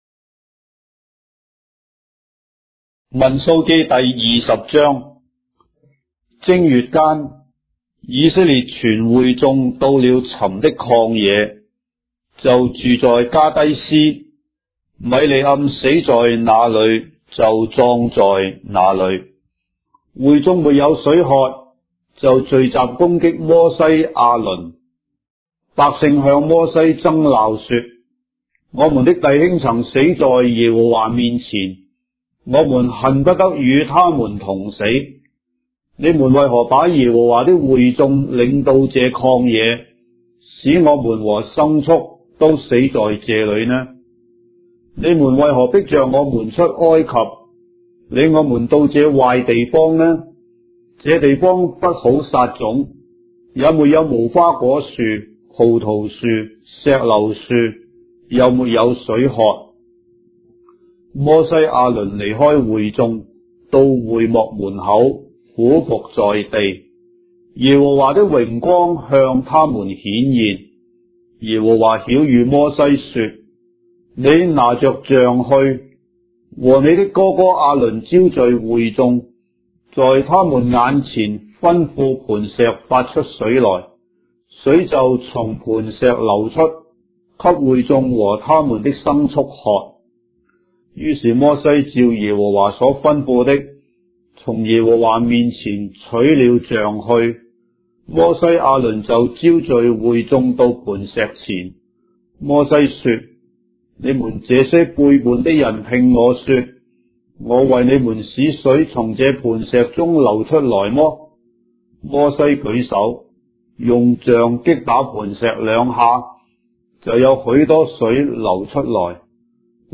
章的聖經在中國的語言，音頻旁白- Numbers, chapter 20 of the Holy Bible in Traditional Chinese